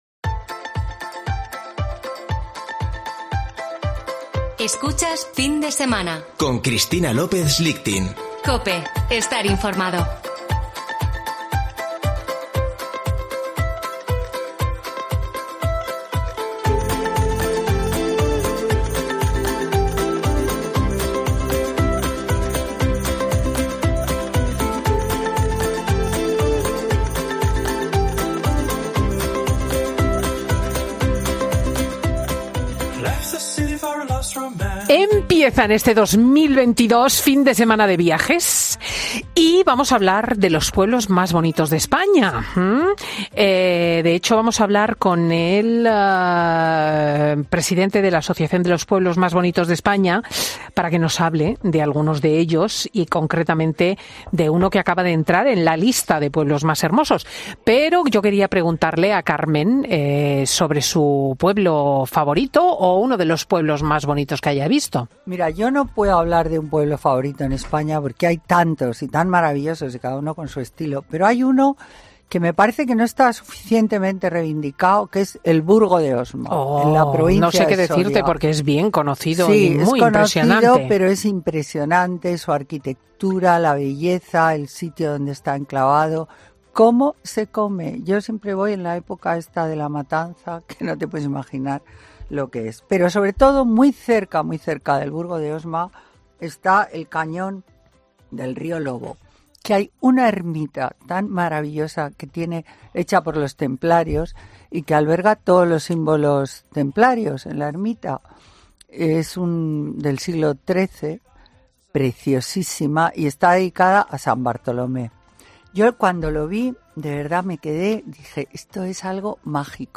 Redacción digital Madrid - Publicado el 08 ene 2022, 13:00 - Actualizado 18 mar 2023, 07:13 3 min lectura Descargar Facebook Twitter Whatsapp Telegram Enviar por email Copiar enlace Escucha ahora 'Fin de Semana' .